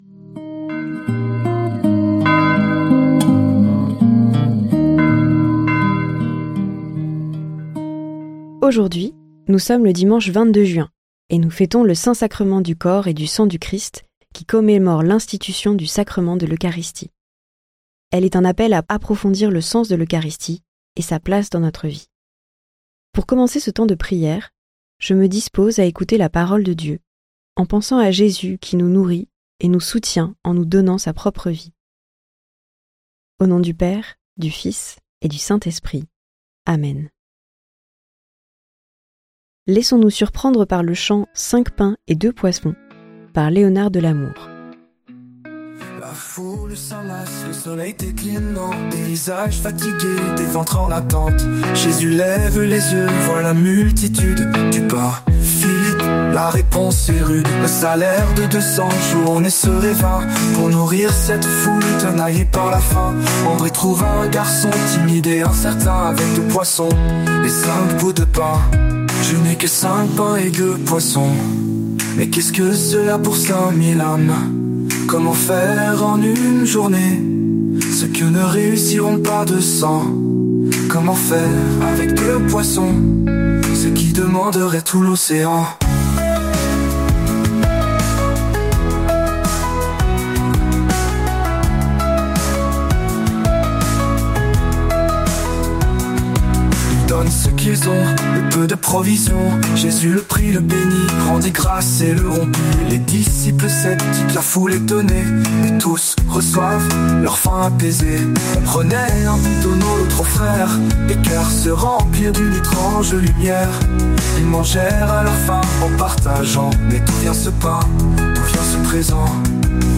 Prière audio avec l'évangile du jour - Prie en Chemin
Musiques